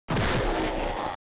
strange noise, you aren't going to be too concerned about aesthetics..
hurt.wav